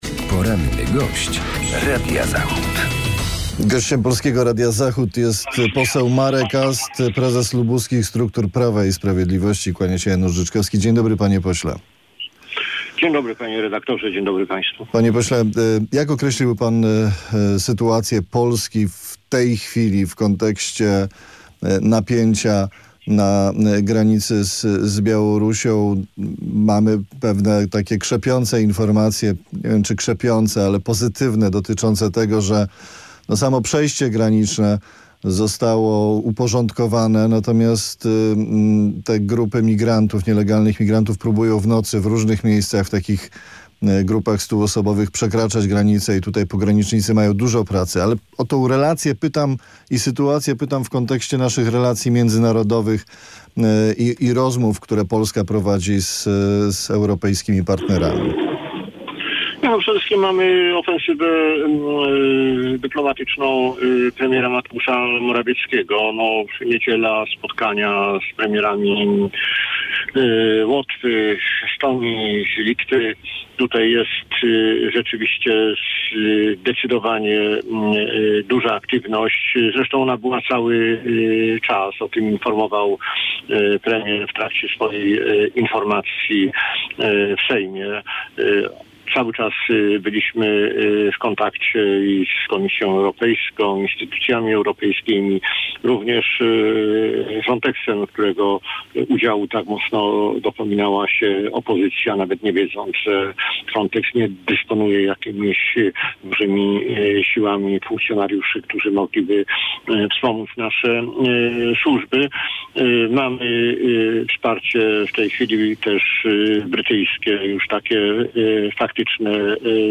Z Markiem Astem poslem Prawa i Sprawiedliwości rozmawia